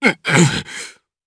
DarkKasel-Vox_Damage_jp_02.wav